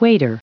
Prononciation du mot waiter en anglais (fichier audio)
Prononciation du mot : waiter